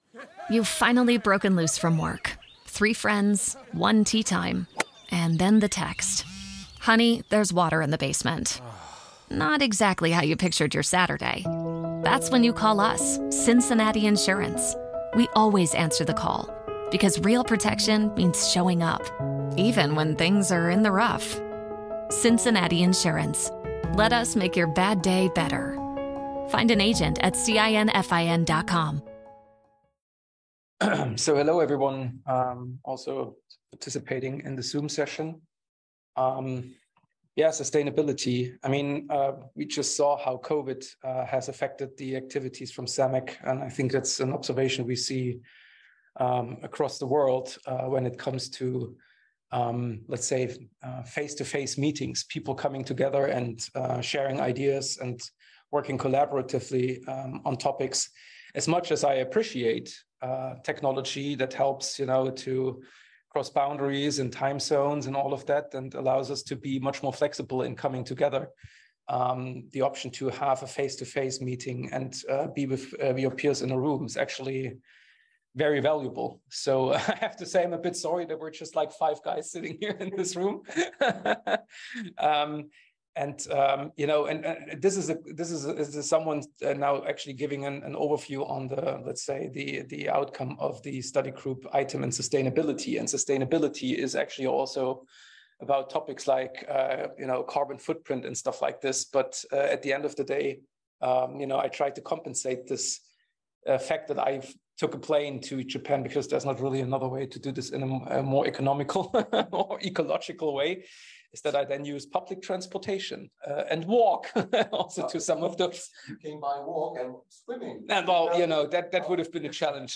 Beschreibung vor 2 Jahren Diese Podcast Folge (als Ausnahme in Englisch) ist der Mitschnitt meiner Präsentation im Rahmen des ISO Plenary Meetings in Okayama, Japan, zum Thema IT Asset Management und Sustainability oder in Deutsch "Nachhaltigkeit".
Bei dieser Podcast Folge handelt es sich - konsequenterweise für einen Podcast - nur um die "Tonspur" der Präsentation.